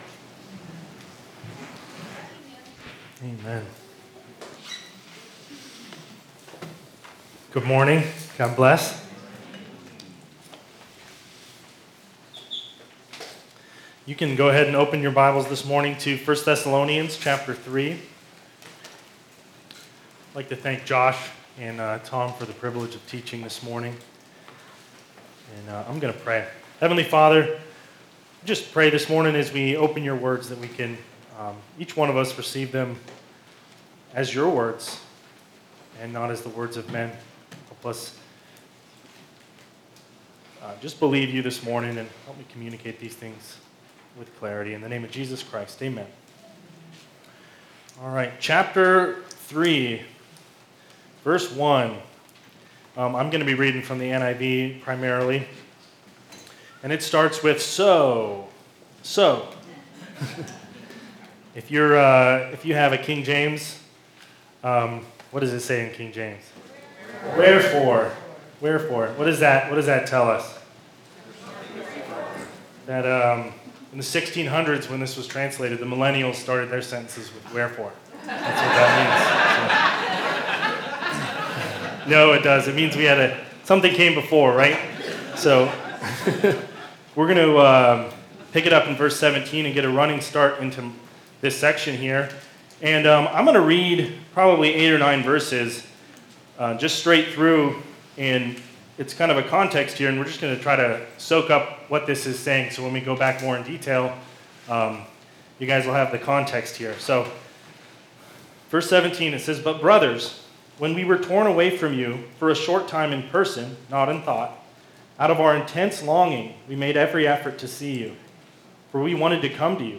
Part 5 in a verse-by-verse teaching series on 1 and 2 Thessalonians with an emphasis on how our hope helps us to live holy lives until Christ returns.
1 Thessalonians 3 Our Daily Hope (Family Camp 2024) – Part 5 July 30, 2024 Part 5 in a verse-by-verse teaching series on 1 and 2 Thessalonians with an emphasis on how our hope helps us to live holy lives until Christ returns.